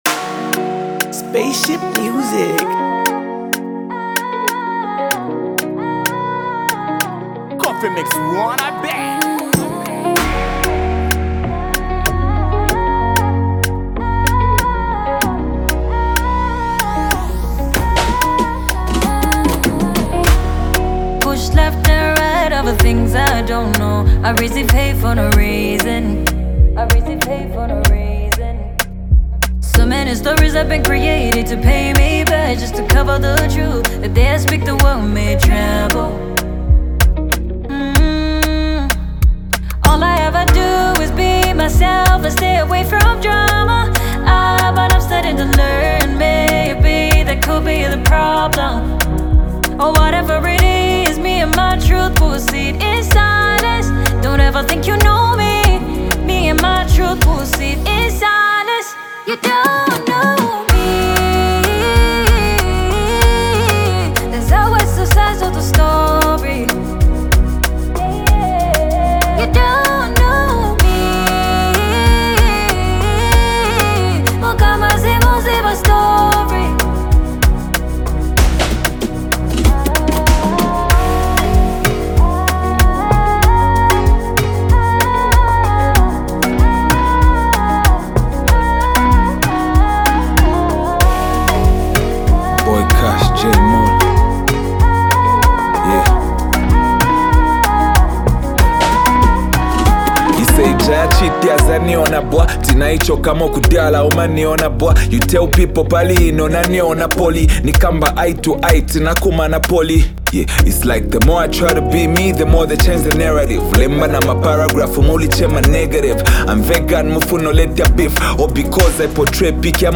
brings his signature smooth flow to complement her vocals